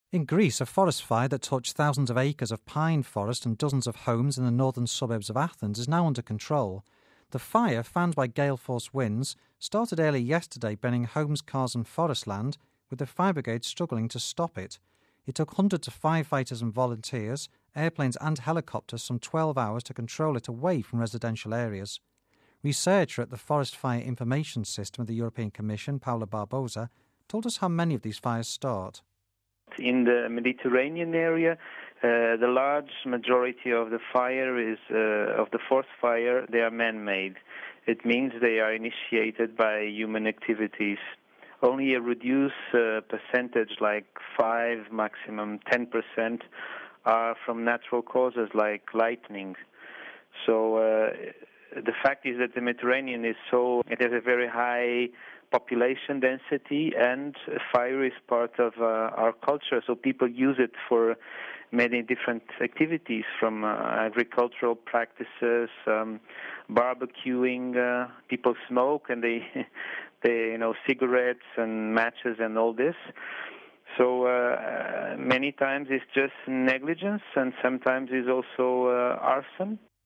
Home Archivio 2007-08-17 18:50:21 Forest Fire Under Control in Greece (17 Aug '07 - RV) A forest fire that destroyed dozens of homes in Athens, Greece, is now under control after it raged for hours. An expert speaks to us about the blaze...